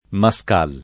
vai all'elenco alfabetico delle voci ingrandisci il carattere 100% rimpicciolisci il carattere stampa invia tramite posta elettronica codividi su Facebook Mascal [ ma S k # l ] n. pr. m. — la festa della Croce, in Etiopia